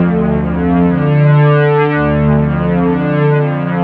cch_synth_loop_hardy_125_Dm.wav